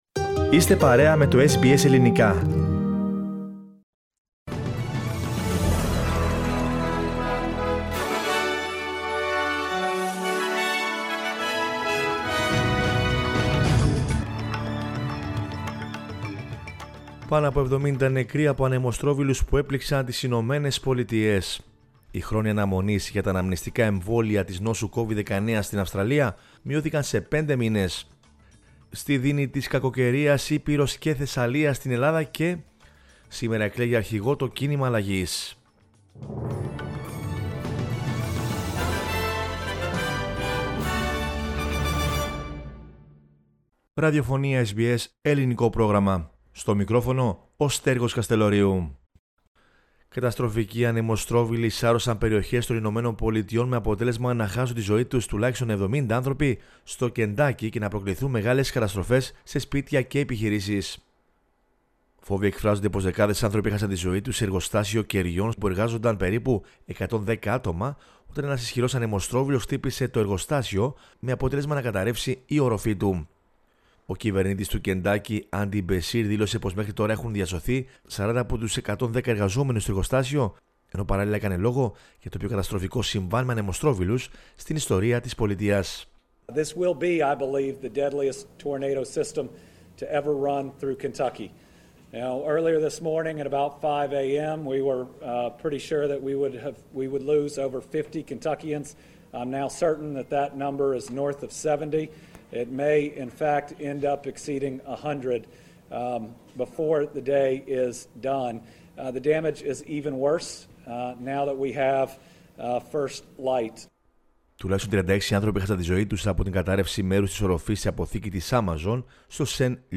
News in Greek from Australia, Greece, Cyprus and the world is the news bulletin of Sunday 12 December 2021.